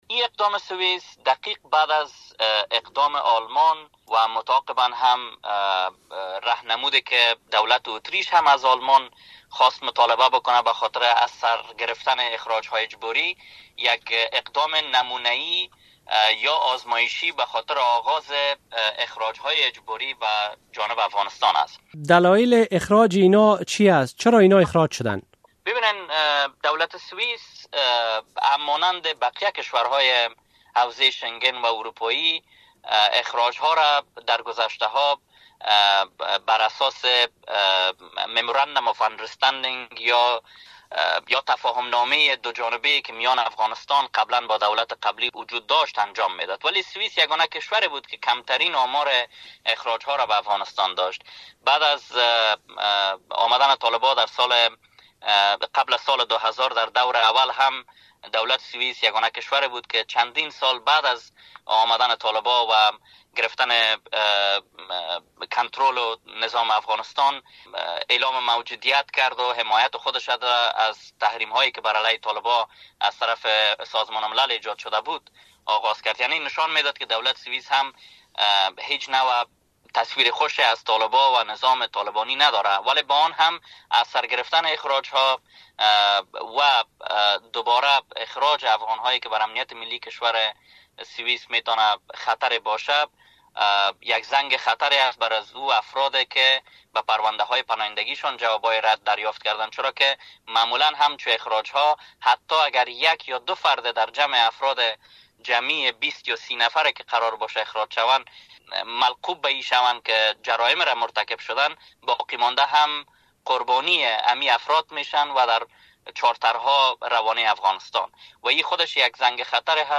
مصاحبه | سویس برای نخستین بار پس از سال ۲۰۱۹ اقدام به اخراج پناهندگان افغان کرده است